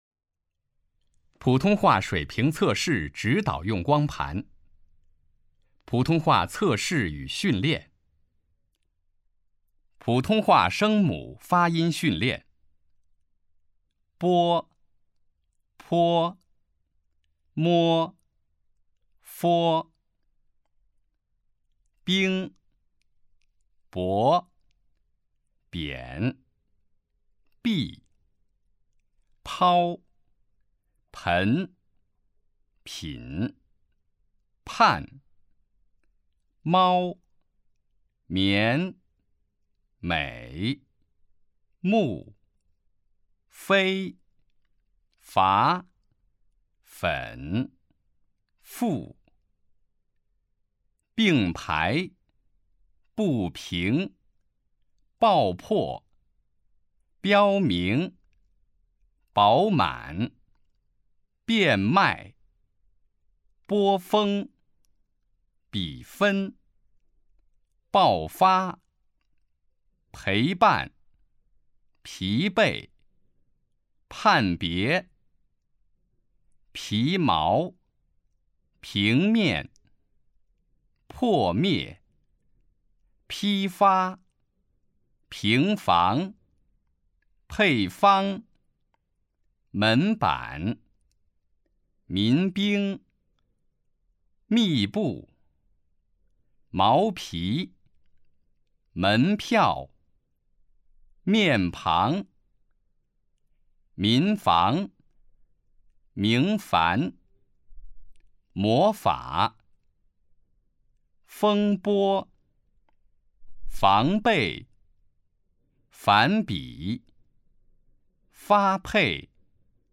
001号普通话声母发音训练.mp3